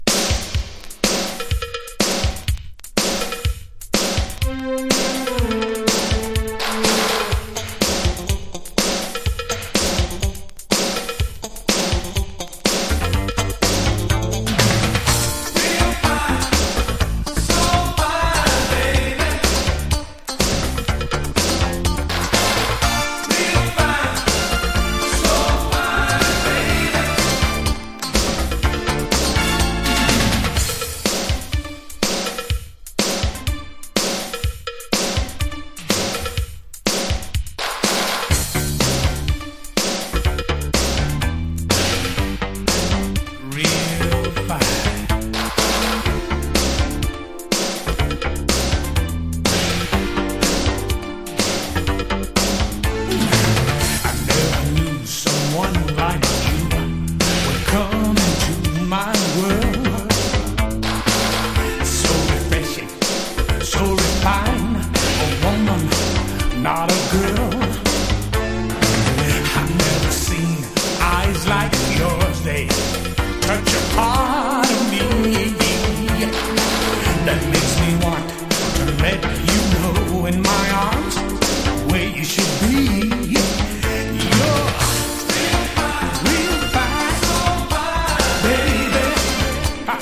所によりノイズありますが、リスニング用としては問題く、中古盤として標準的なコンディション。